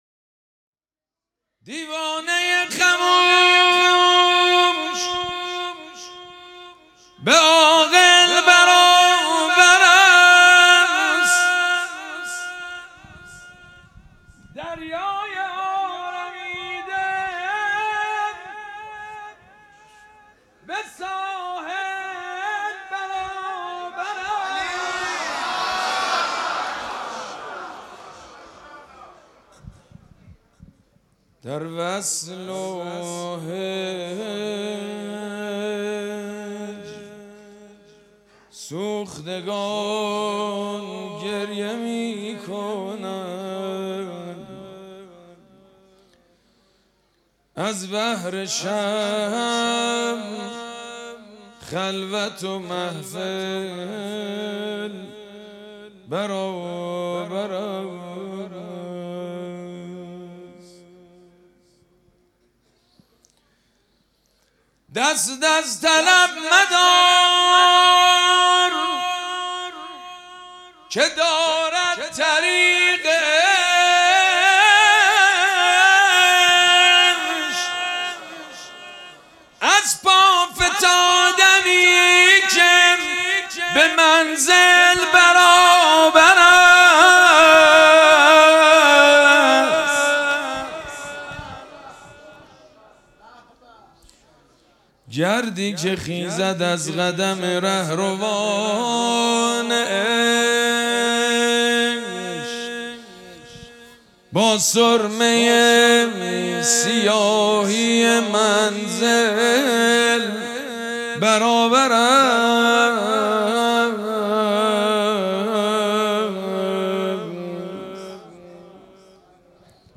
مراسم جشن ولادت حضرت صاحب الزمان (عج)
حسینیه ریحانه الحسین سلام الله علیها
شعر خوانی